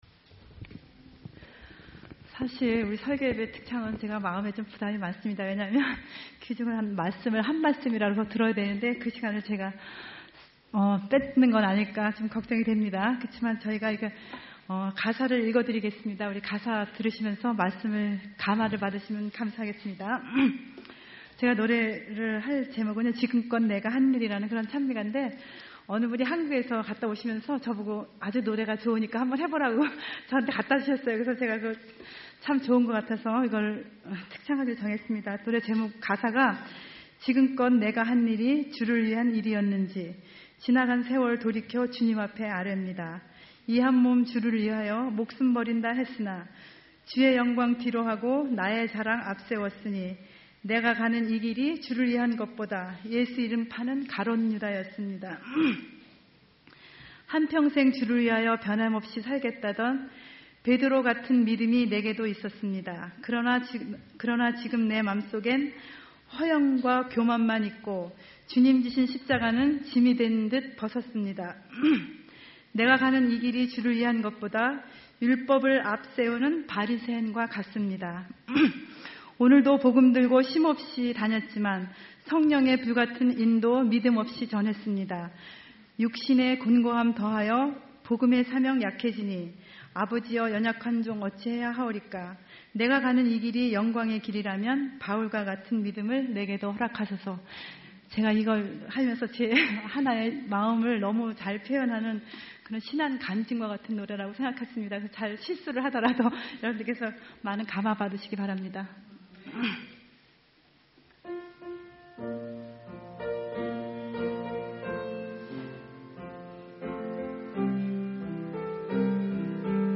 special music, sermon